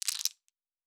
Objects Small 12.wav